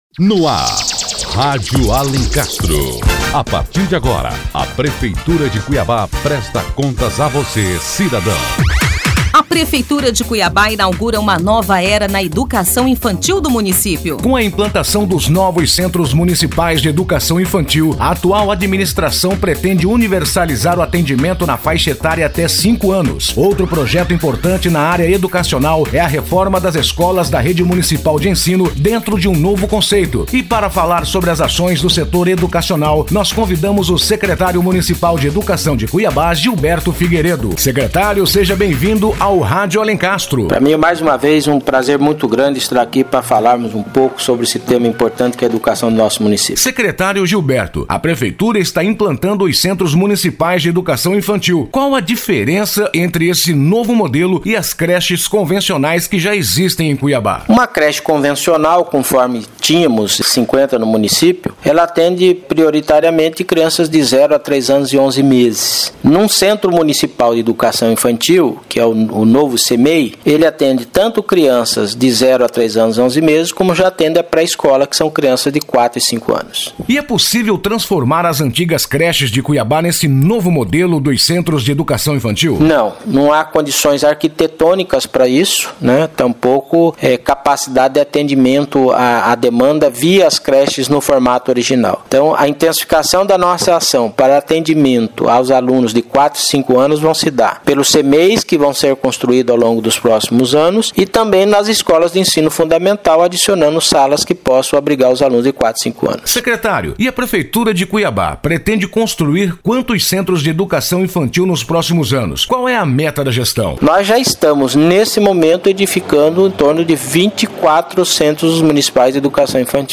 O Secretário Municipal de Educação, Gilberto Figueiredo, fala sobre as principais mudanças que estão ocorrendo...